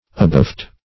abaft - definition of abaft - synonyms, pronunciation, spelling from Free Dictionary
Abaft \A*baft"\ ([.a]*b[.a]ft"), prep.